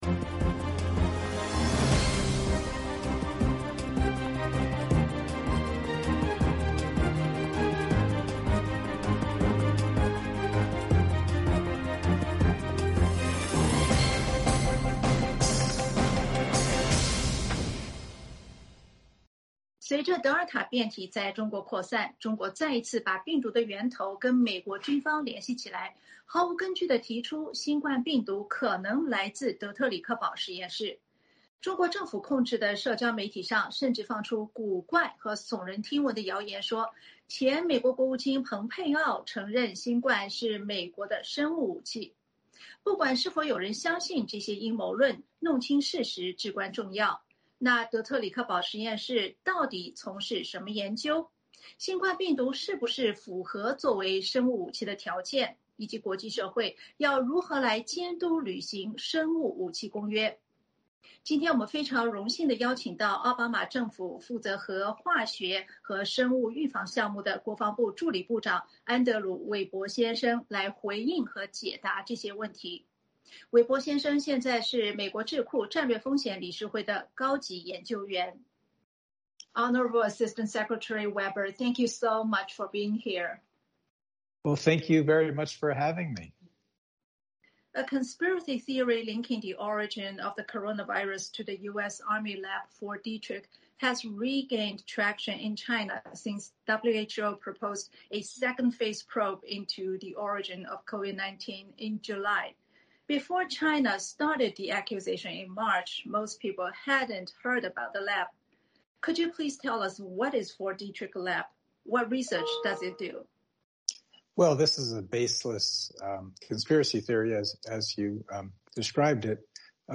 本期《时事大家谈》由近20分钟的专访再加上以往节目的精彩讨论组合而成。
《时事大家谈》邀请到奥巴马政府负责核、化学和生物防御项目的国防部助理部长安德鲁·韦伯(Andrew Weber)来回应和解答。